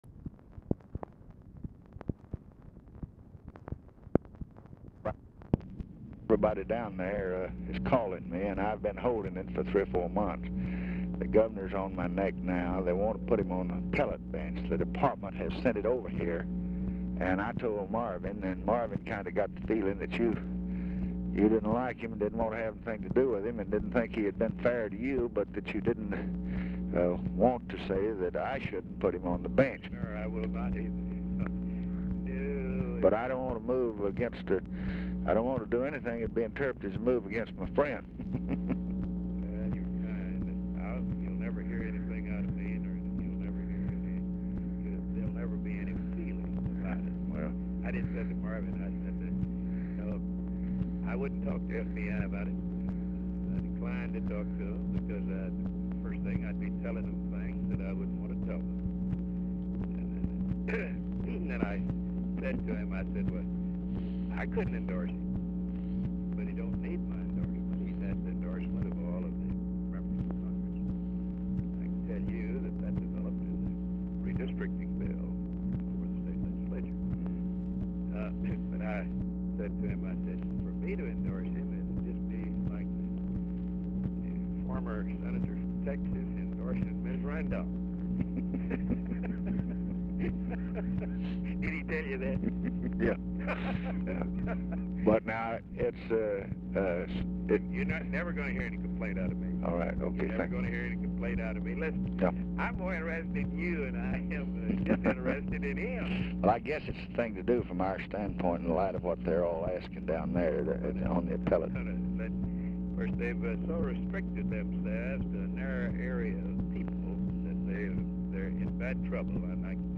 RECORDING STARTS AFTER CONVERSATION HAS BEGUN; CLEMENTS IS DIFFICULT TO HEAR
Format Dictation belt
Specific Item Type Telephone conversation Subject Appointments And Nominations Humor And Mimicry Judiciary National Politics Texas Politics